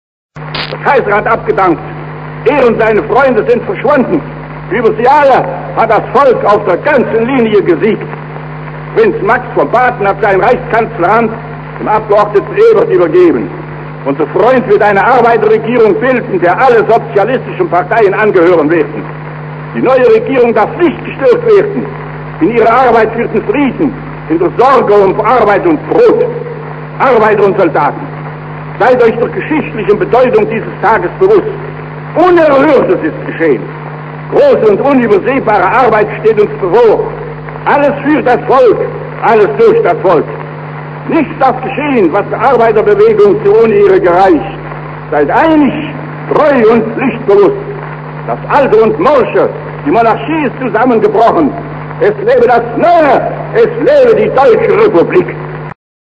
Scheidemann ruft am 9. November 1919 vom Westbalkon des Reichstags die Deutsche Demokratie aus
scheidemann_ausrufung.wav